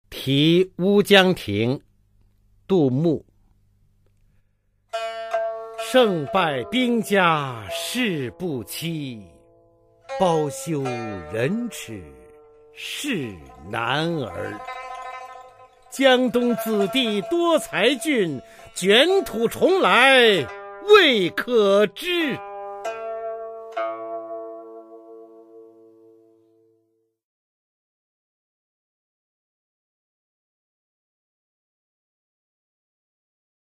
[隋唐诗词诵读]杜牧-题乌江亭 古诗文诵读